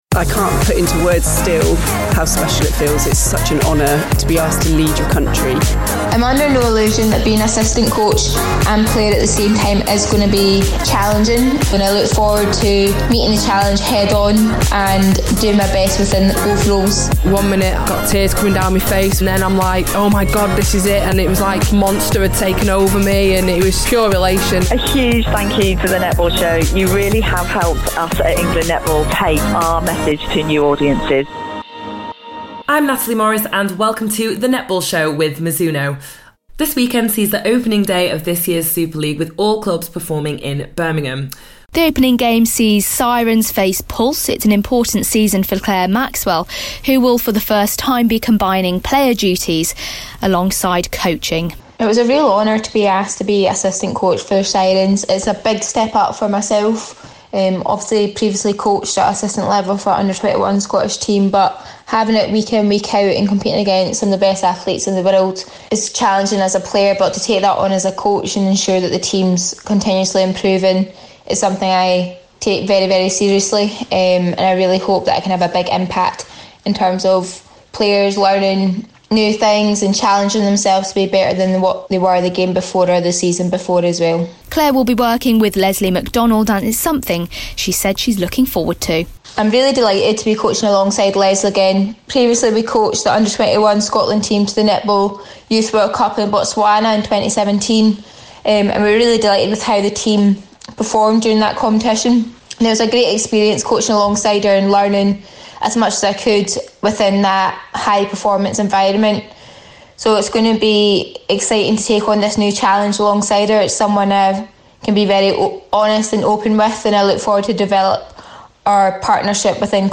The Netball Show's annual look ahead to the season opener - In Part One we hear from coaches and players taking part this weekend